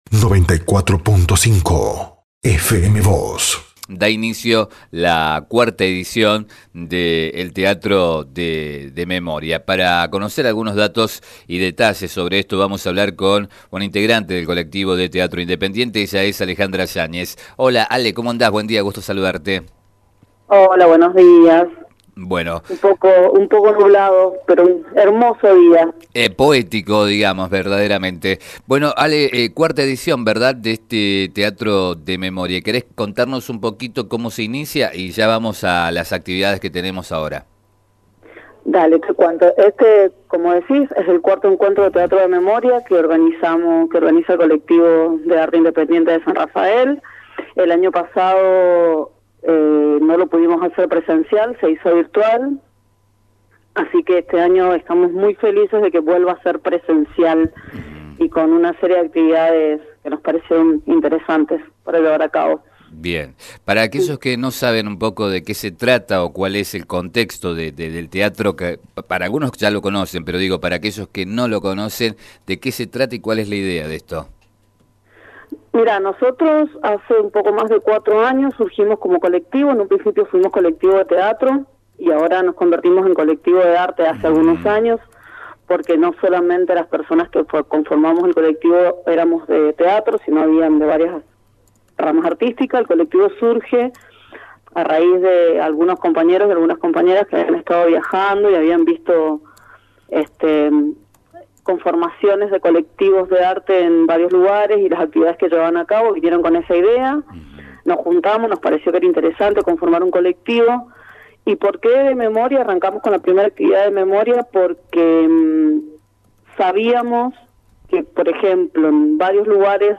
Miembros del Colectivo de Arte Independiente de San Rafael llevarán a cabo el cuarto encuentro de Teatro de Memoria, que se desarrollará de manera presencial. Al respecto brindó algunos detalles a FM Vos (94.5) y Diario San Rafael